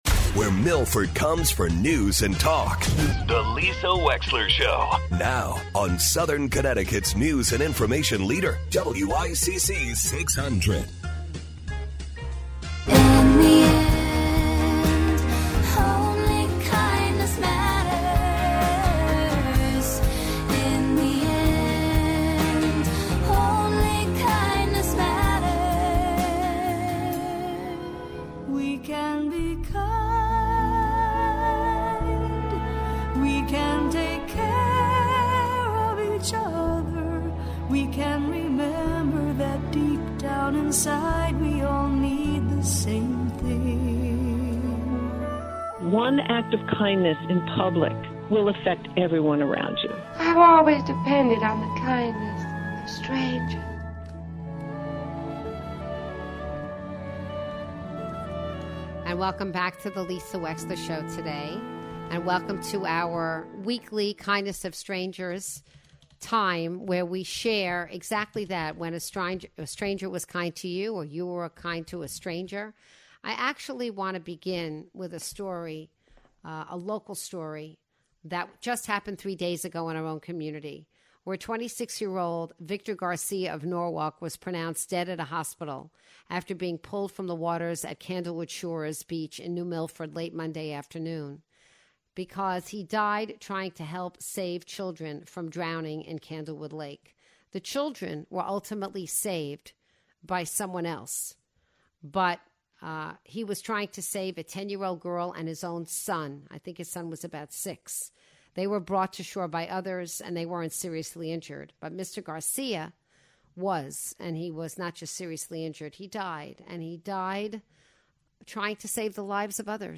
reads your stories and takes your calls in this week's edition of The Kindness of Strangers!